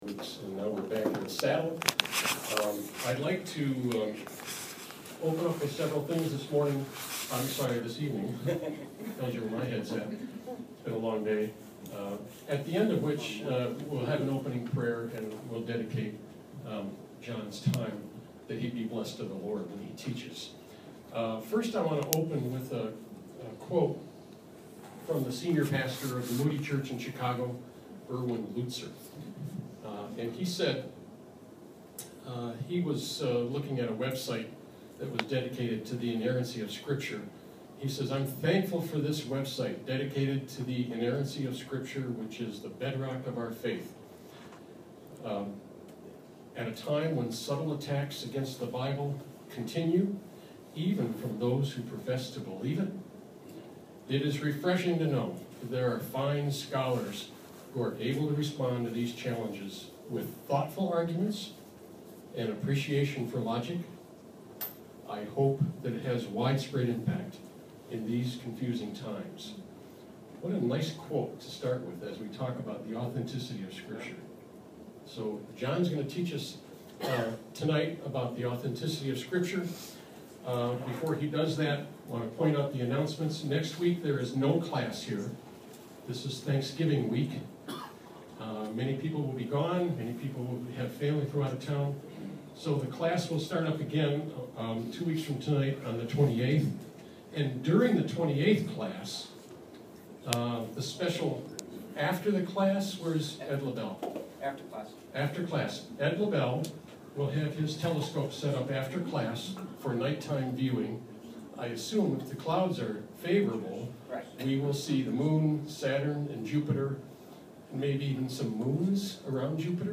Please join us this week at Defending Christianity for Lecture 5 of the Introductory Module.